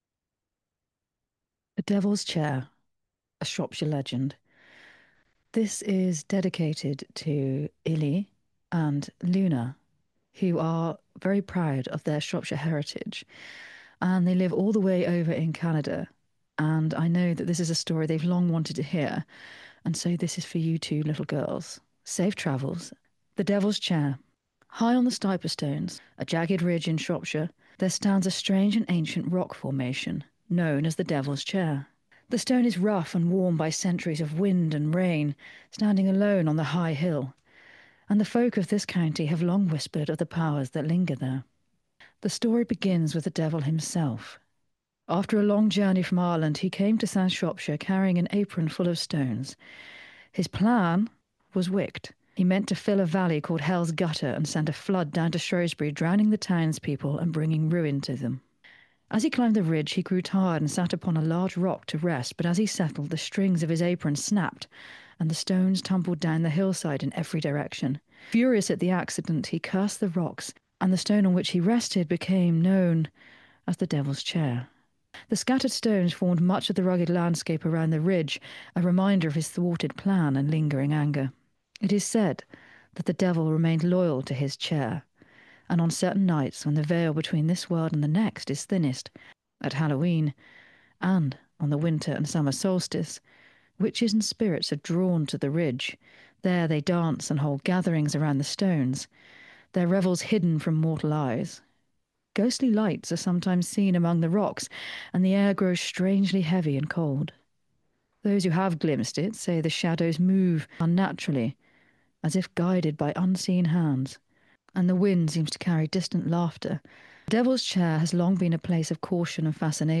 In this narration, I take you to that solitary chair, exploring its history and its spirits.